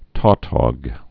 (tôtôg, -tŏg, tô-tôg, -tŏg)